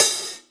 paiste hi hat4 half.wav